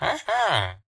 villager
yes2.ogg